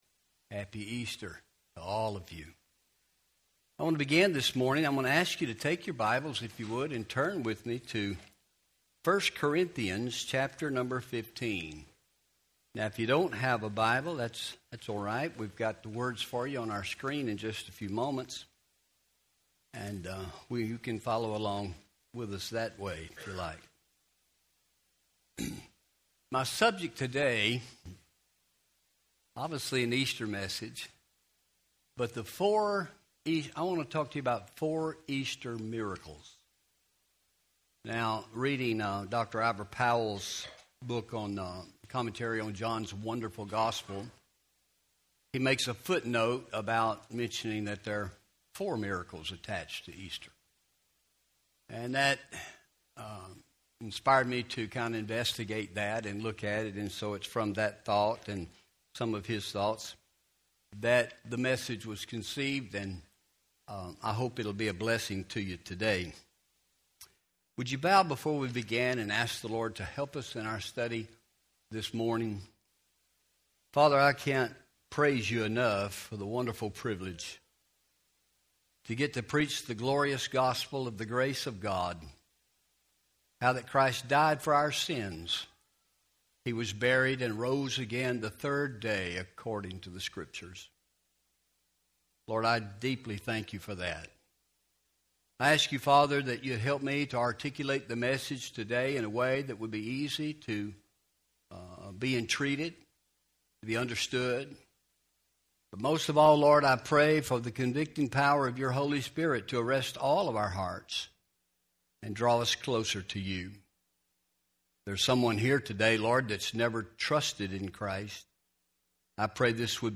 1 Corinthians 15 Easter Sunday 2026